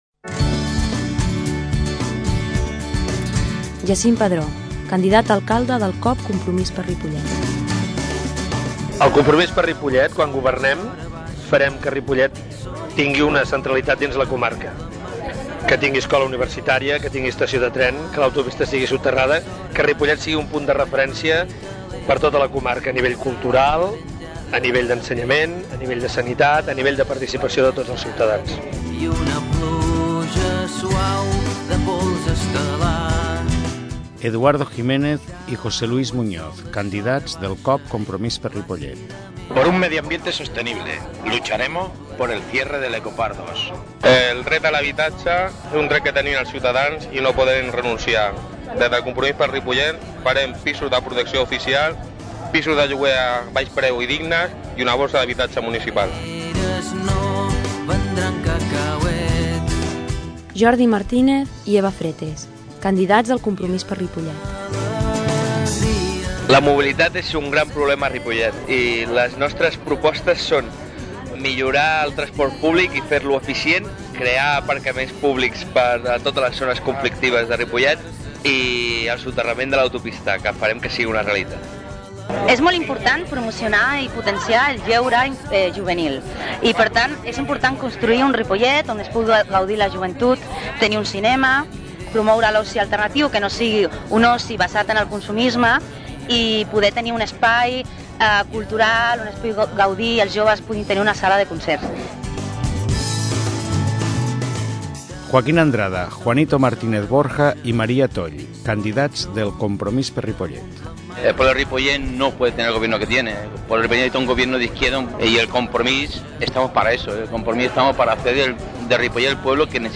Política MUNICIPALS 2007 - Espai de propaganda del CpR -Política- 14/05/2007 Descarregueu i escolteu l'espai radiof�nic enregistrats per aquest partit pol�tic de Ripollet a l'emissora municipal.